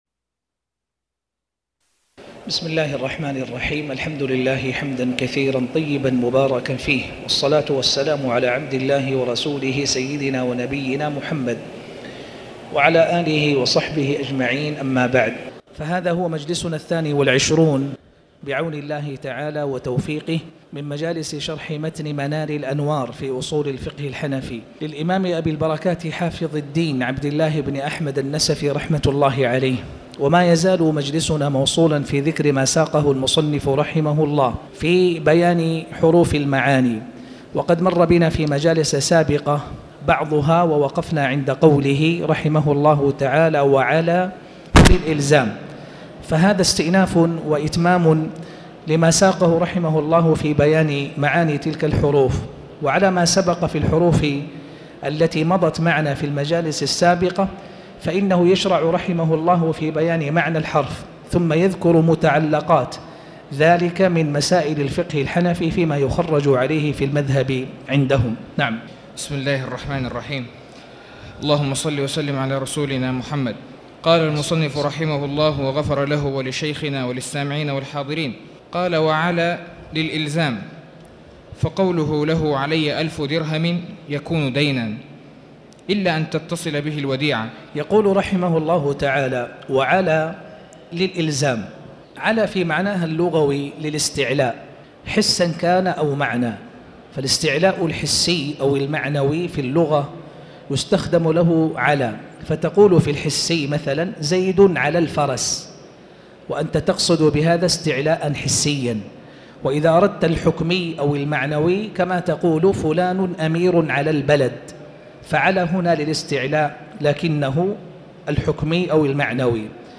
تاريخ النشر ١٢ جمادى الآخرة ١٤٣٩ هـ المكان: المسجد الحرام الشيخ